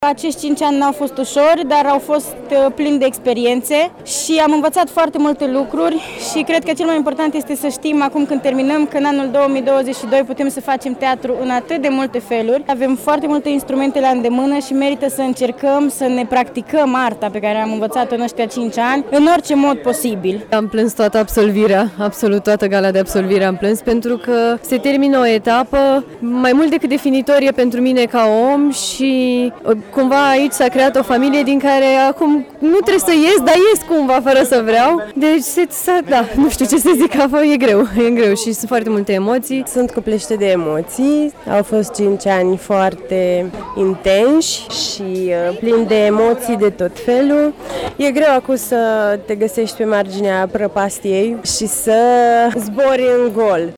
Studenții din an terminal ai Universității de Arte din Târgu Mureș au marcat sâmbătă, la Teatrul Studio 2.0 din municipiu, momentul încheierii studiilor.
Plini de emoție, absolvenții spun că vor să continue să facă teatru: